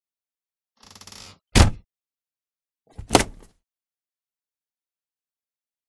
Jet; Cockpit Door; Boeing 707 Cockpit Door Opened And Closed.